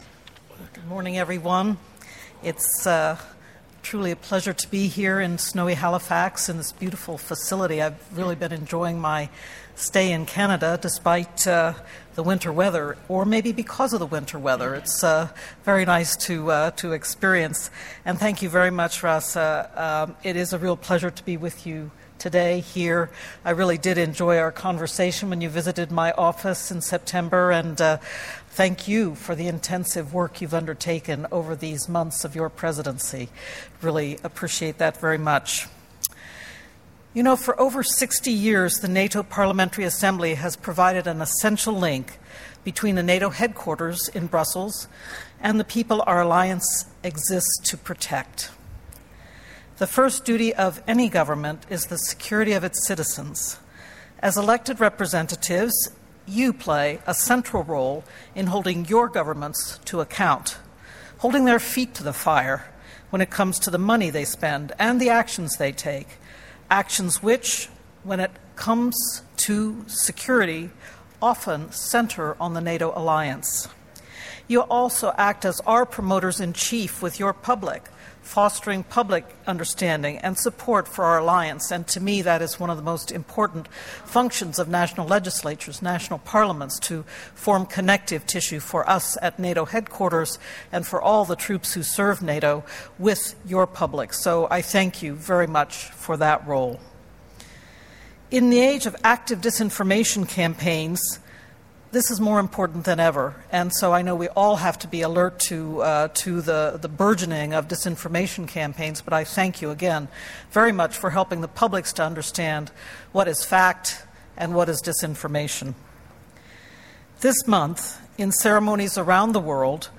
Deputy Secretary General addresses NATO Parliamentary Assembly in Halifax
NATO Deputy Secretary General Rose Gottemoeller stressed the importance of Alliance unity in a keynote address to the 64th Annual Session of the NATO Parliamentary Assembly in Halifax, Canada on Monday (19 November 2018). Noting that unity among allies has underpinned Euro-Atlantic security for almost seventy years, she thanked parliamentarians for their support for NATO.